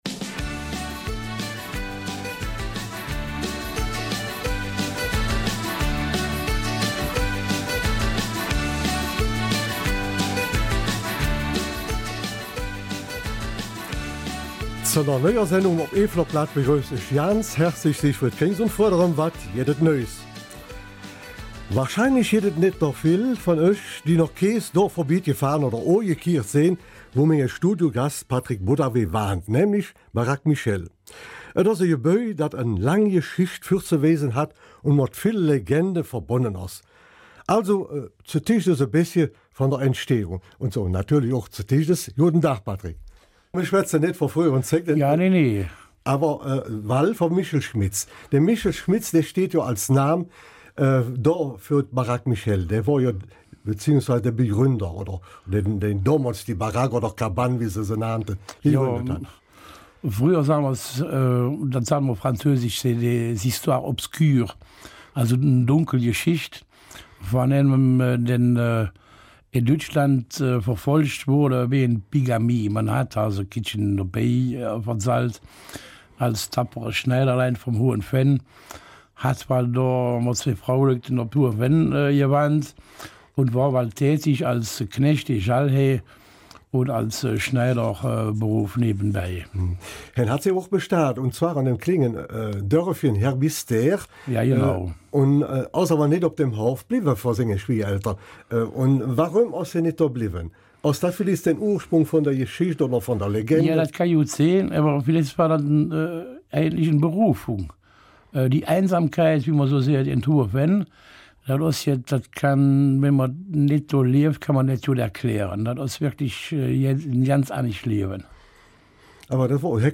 Eifeler Mundart: Die ''Baraque Michel''